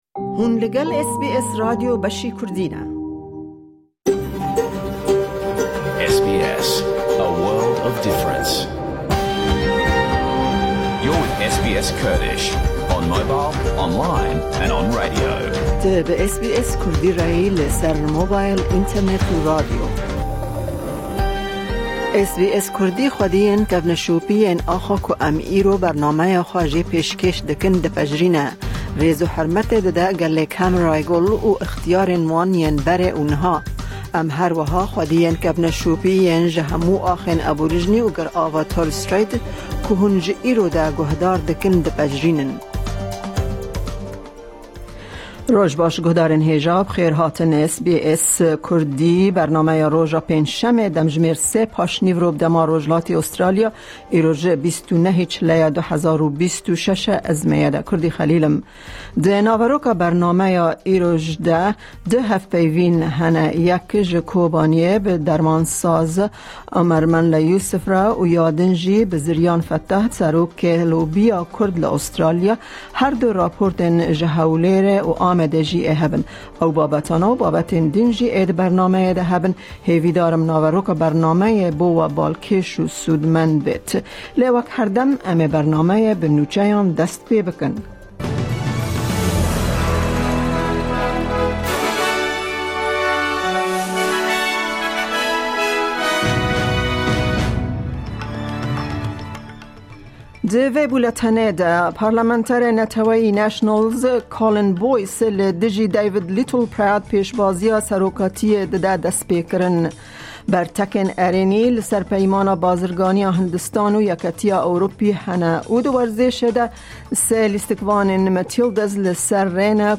Nûçe, raportên ji Hewlêr û Amedê, du hevpeyvîn têde hene